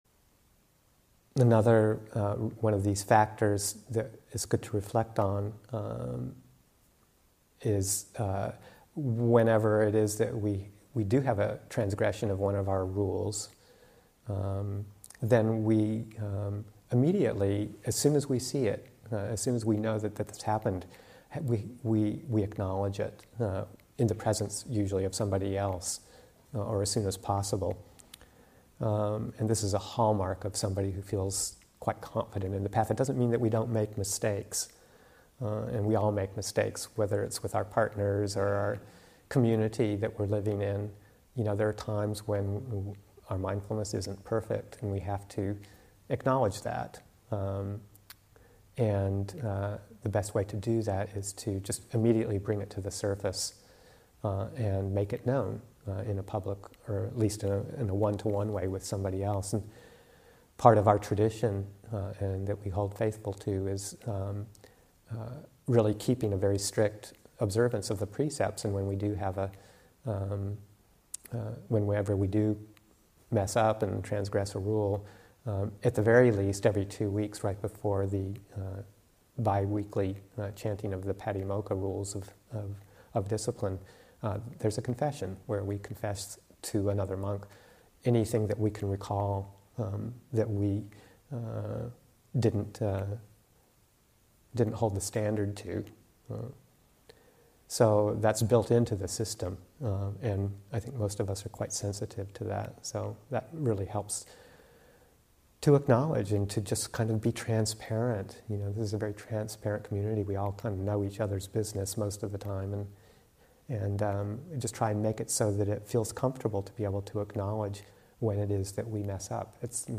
13. Reviewing knowledge #4: Immediately acknowledging our mistakes. Teaching